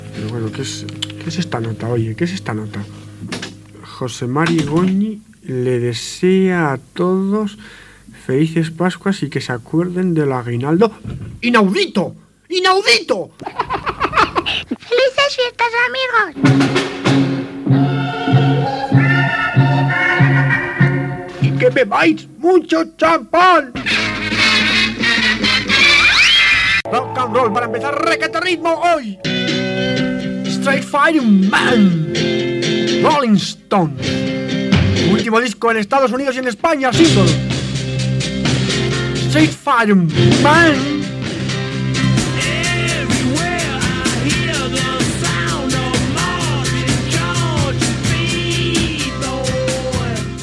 Fragment del programa "Requeteritmo" de Joaquín Luqui, a Radio Requeté de Pamplona.
Musical